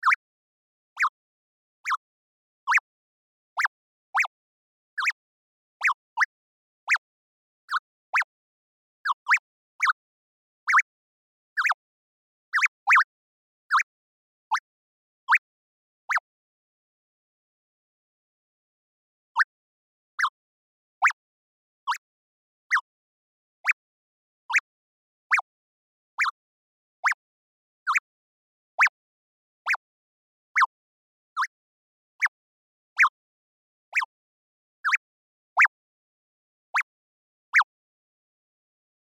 ピヨ コミカルムーブ
/ F｜演出・アニメ・心理 / F-18 ｜Move コミカルな動き